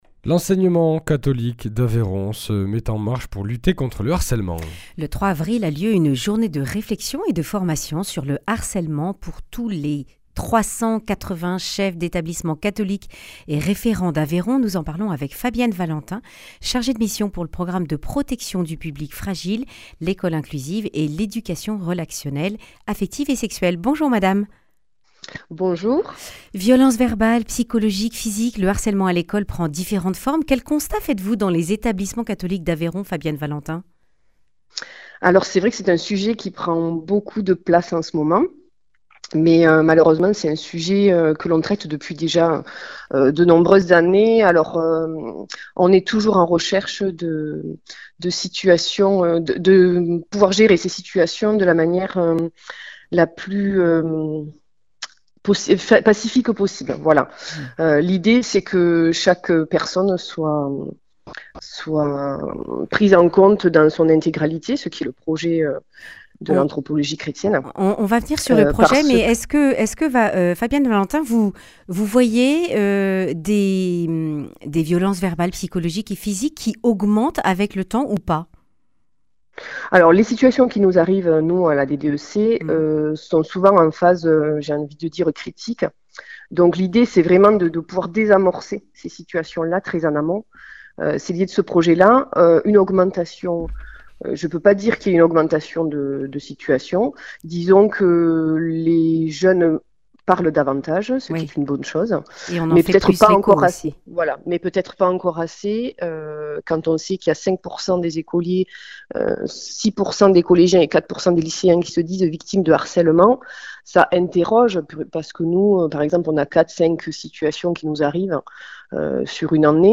Accueil \ Emissions \ Information \ Régionale \ Le grand entretien \ Les établissements catholiques d’Aveyron résolus pour lutter contre le (…)